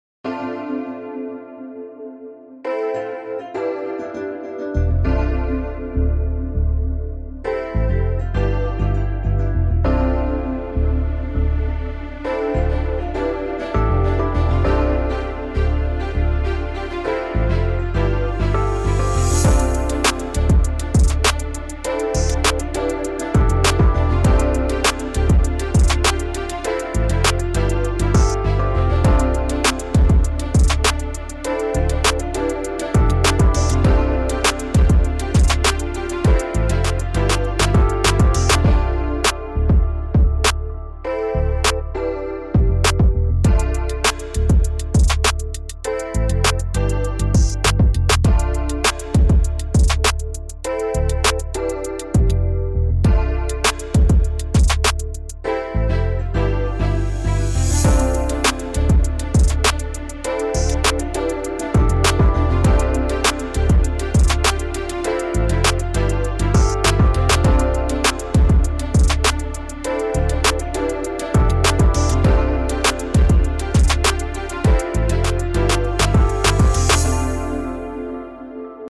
Enjoy this fire beat!!!